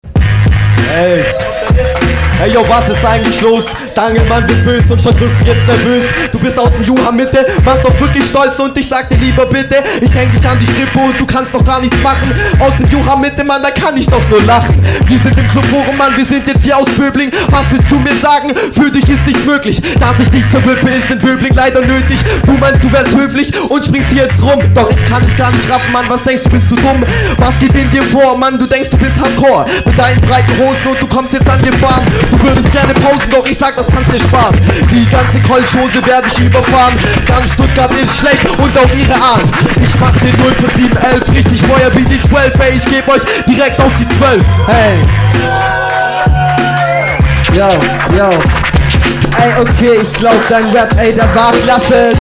ich poste mal bei gelegenheit nen freestyle vom nem typen aus Böblingen (da komm ich her)...dann weisste was geht ;)